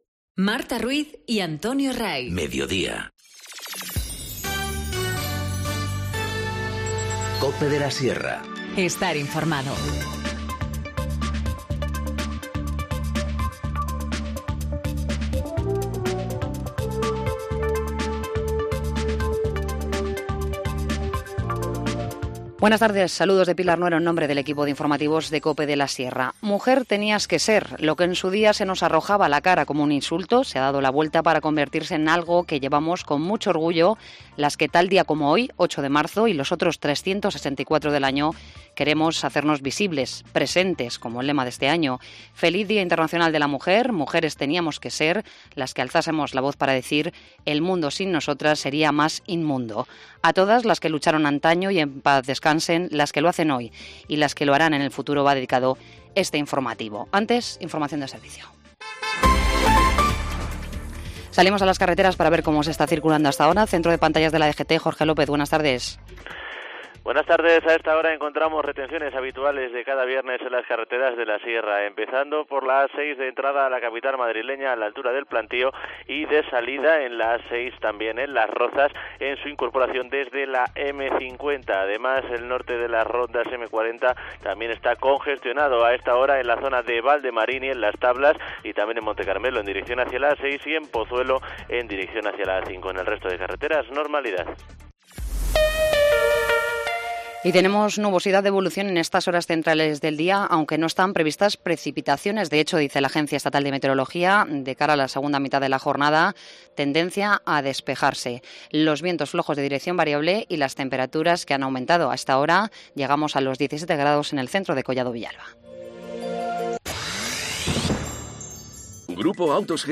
Informativo Mediodía 8 marzo 14:20h